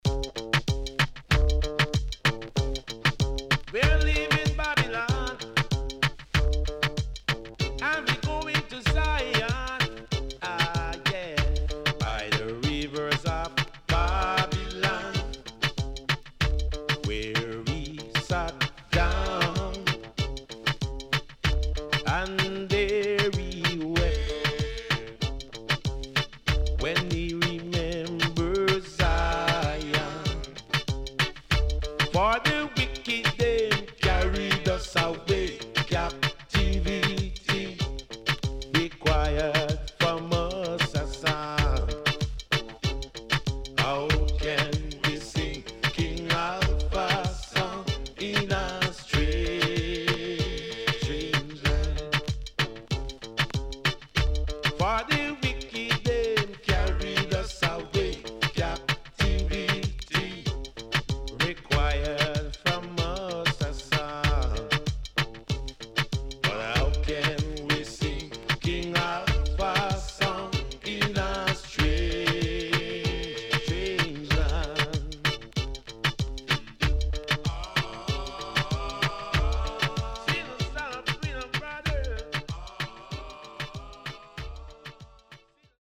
HOME > LP [DANCEHALL]
SIDE B:少しチリノイズ入りますが良好です。